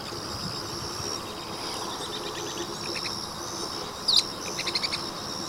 Spix´s Spinetail (Synallaxis spixi)
Life Stage: Adult
Condition: Wild
Certainty: Recorded vocal